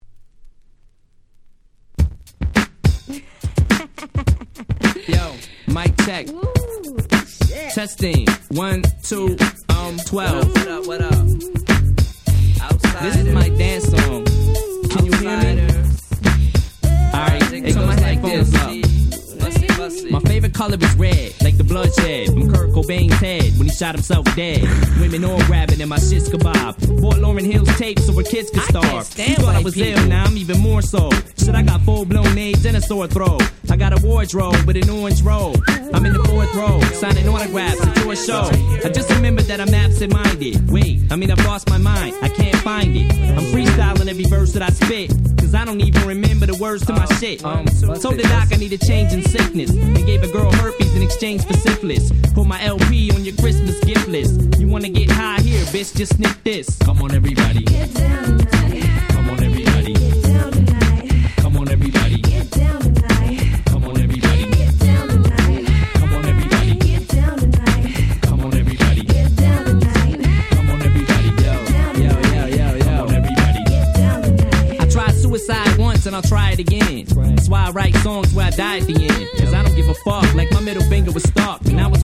90's Boom Bap ブーンバップ